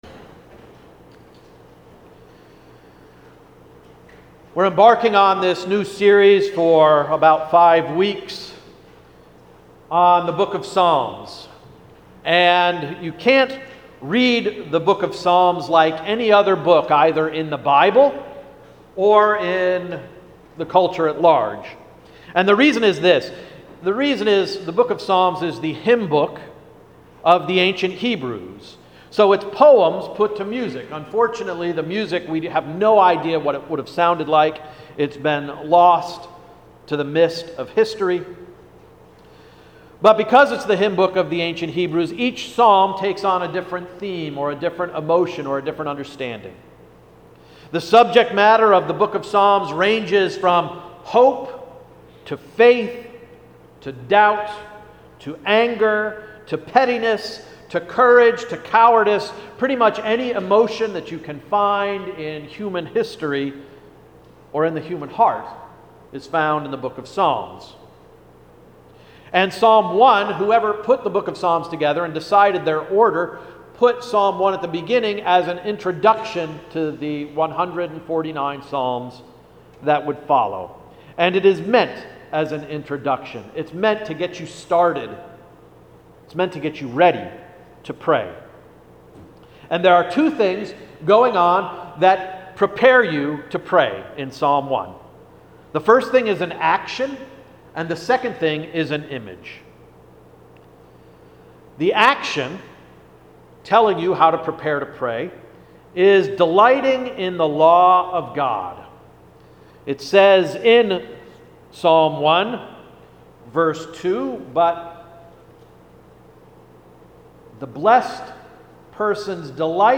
Sermon of July 29 – Emmanuel Reformed Church of the United Church of Christ